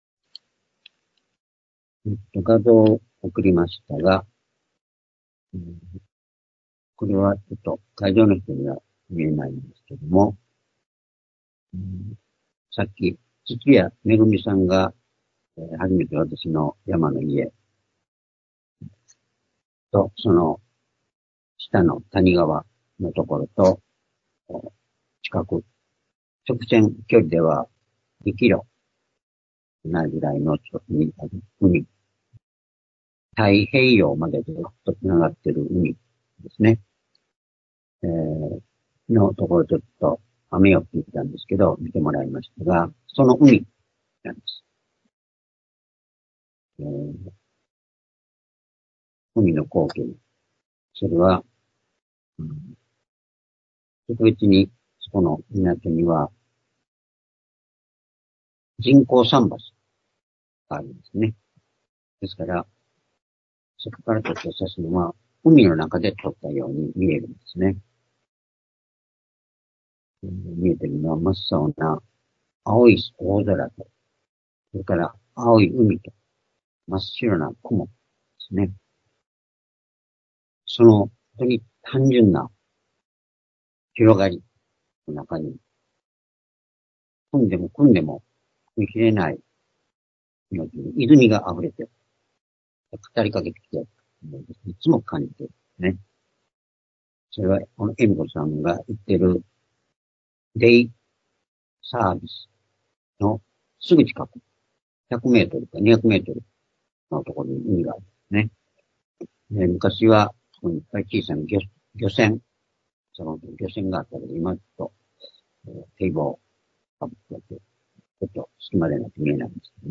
主日礼拝日時 2025年2月9日(主日礼拝) 聖書講話箇所 「イエスのまなざしの広さ、高さ、深さ」 ヨハネ19章25～27節 ※視聴できない場合は をクリックしてください。